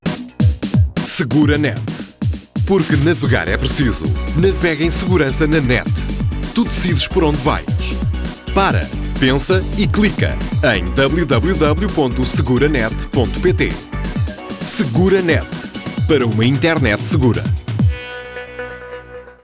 Spot SeguraNet para rádio | SeguraNet